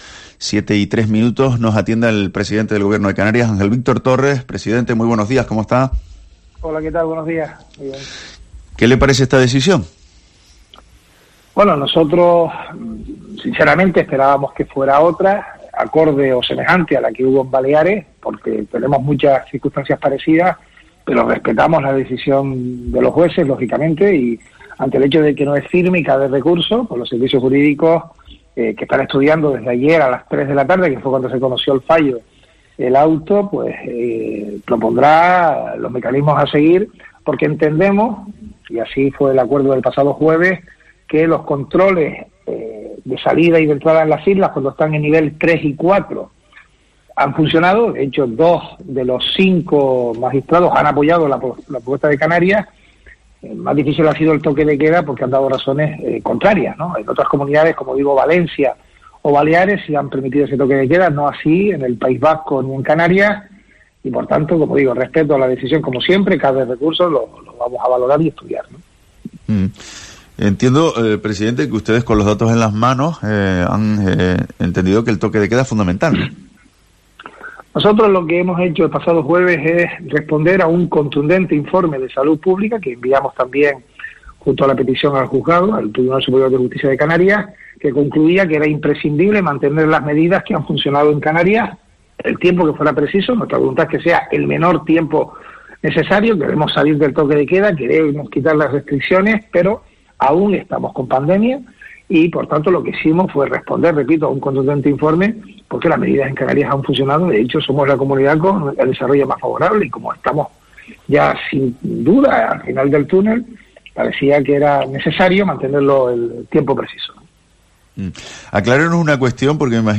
El presidente del Gobierno de Canarias, Ángel Víctor Torres, ha pasado por los micrófonos de COPE Canarias, en su primera entrevista tras la decisión del Tribunal Superior de Justicia de Canarias (TSJC), que no ha avalado el toque de queda, ni las restricciones de entrada y salida de las islas.